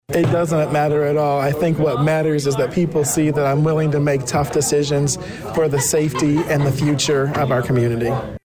Mayor Rickey Williams, Jr emphasized while speaking for the city during the meeting that the situation was getting even more dangerous, with signs of another piece of terra cotta having recently fallen from the seventh or eighth floor.